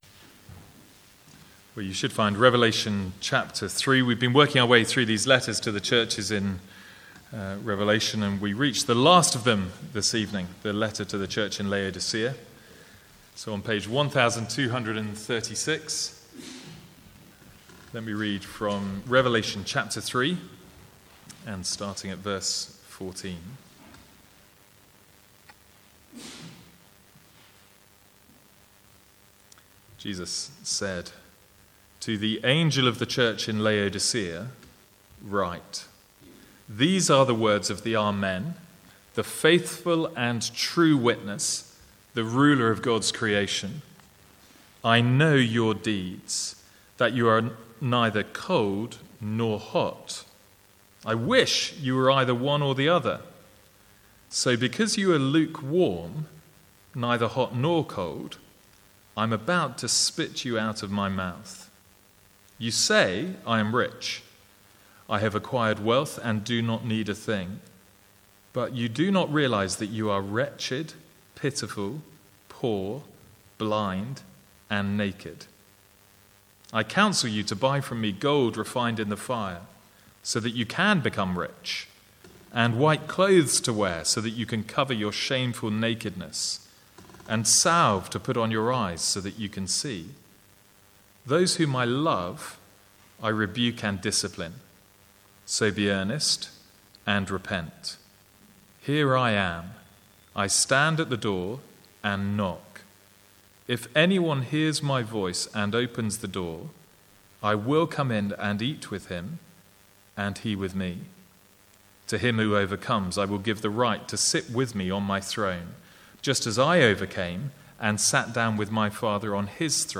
Sermons | St Andrews Free Church
From the Sunday evening series in Revelation (recorded 29/6/14).